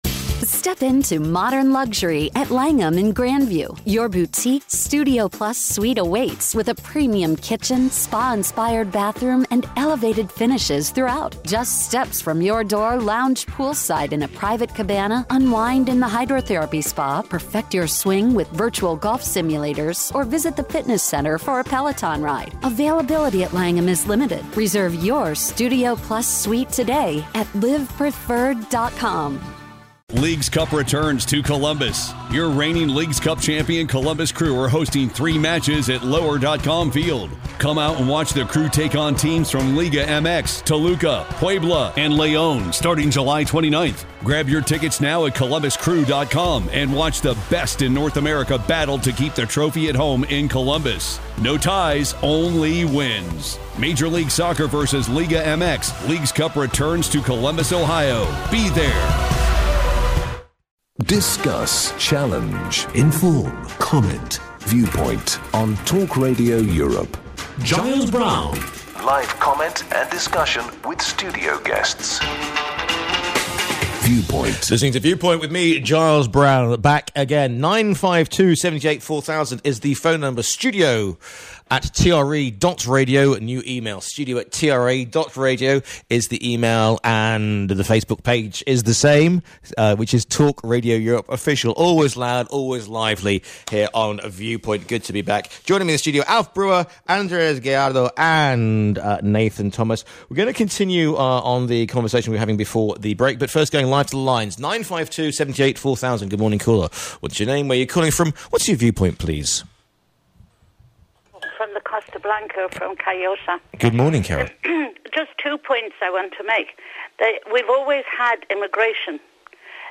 The panel discuss the major and breaking news stories of the week. The show is uncensored, often controversial, and full of heated debate.